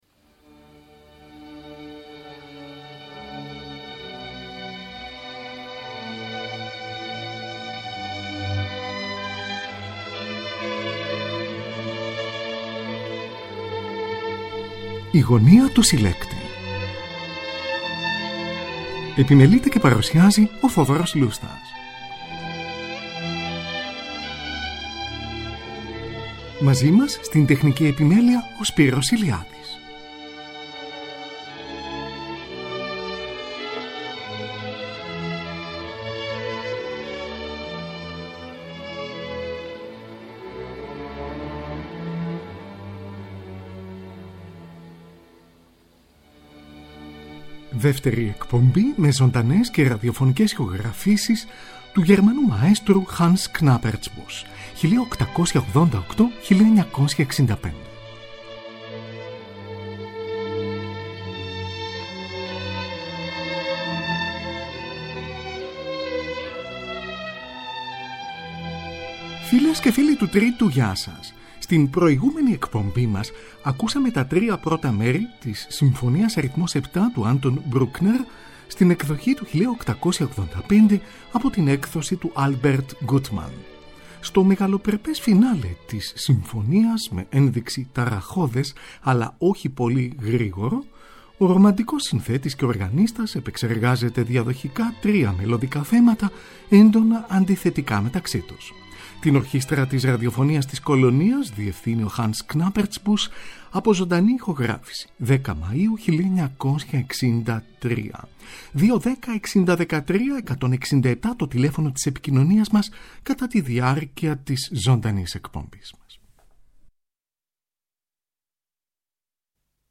Δεύτερη εκπομπή με ζωντανές και ραδιοφωνικές ηχογραφήσεις του μαέστρου HANS KNAPPERTSBUSCH (1888-1965)
Την Ορχήστρα της Ραδιοφωνίας της Κολωνίας διευθύνει ο Hans Knappertsbusch, από ζωντανή ηχογράφηση στις 10 Μαΐου1963.